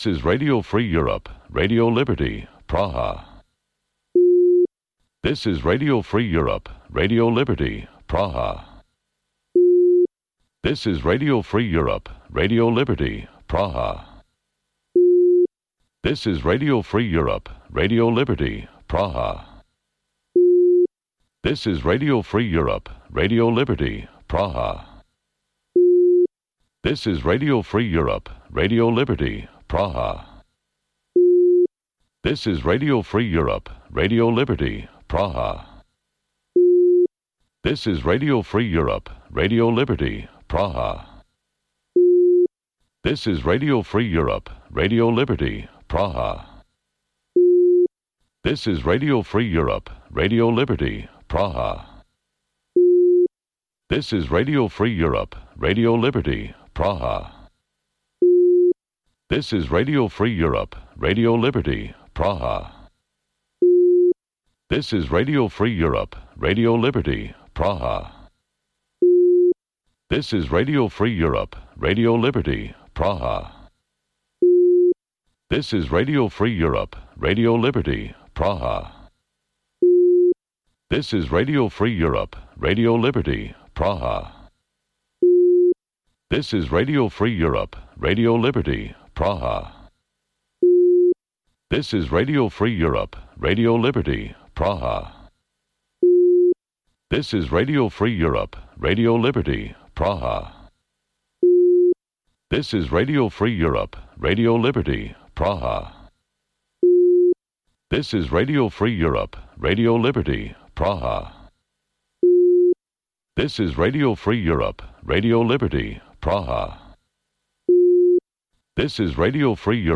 Преглед на вестите и актуелностите од Македонија и светот, како и локални теми од земјата од студиото во Прага.